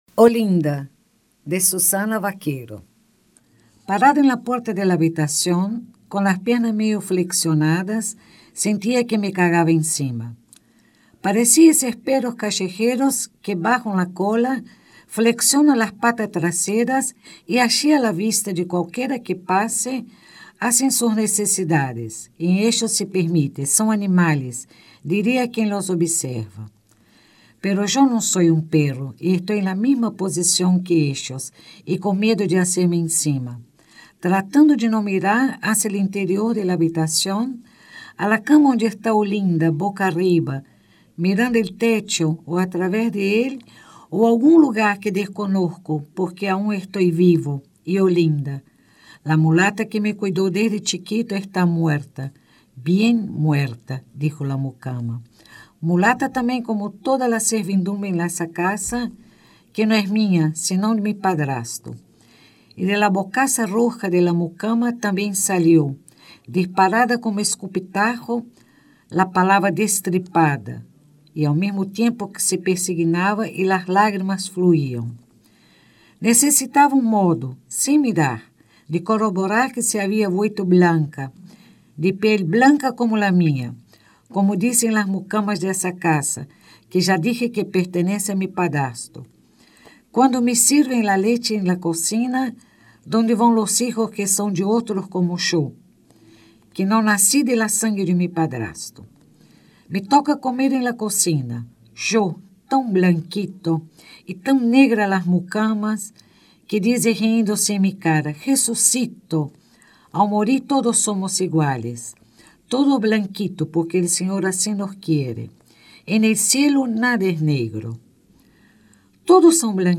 Palabras claves: Narración de cuentos ; Audiolibro